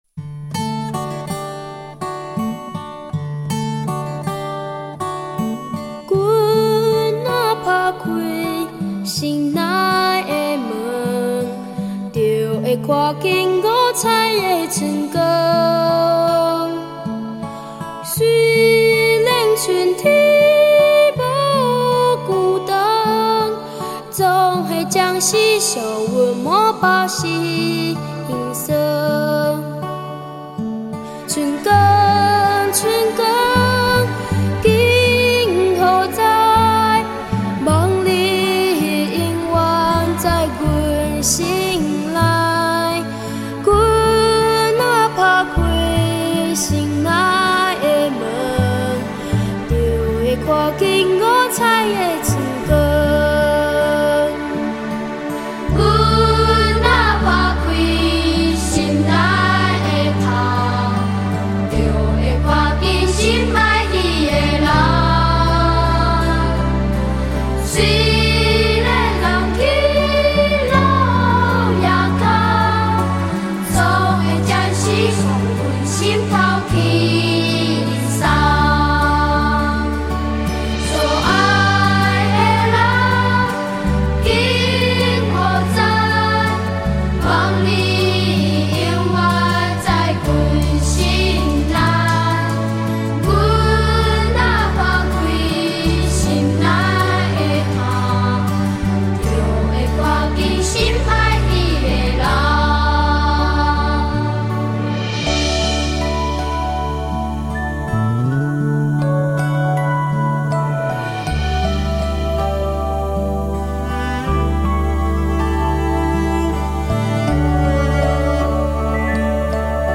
遠離塵囂的翅膀，拍打著無憂的節奏；清亮高亢的歌喉，與樹林間的蟬鳴鳥啼唱和，暫忘了車水馬龍的外面，和紛擾煩雜的平地世界。